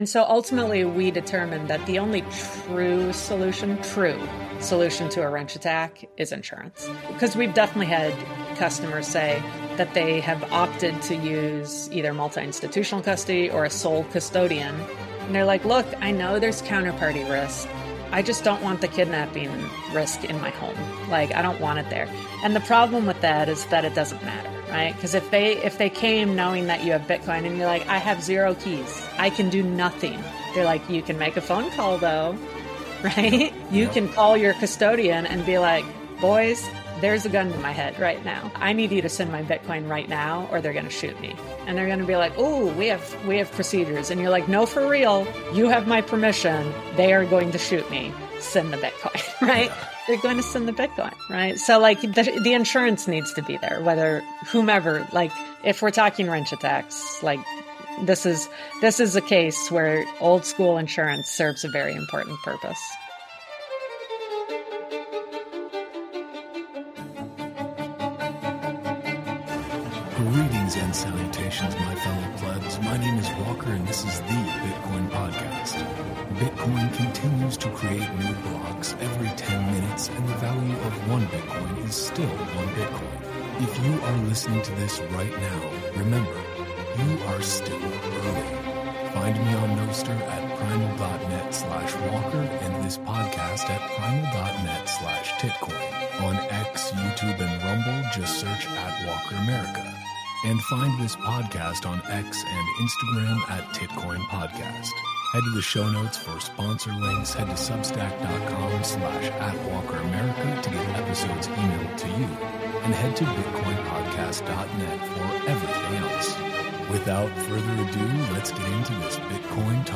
for a conversation on the future of Bitcoin insurance, self-custody security, and wrench attack protection.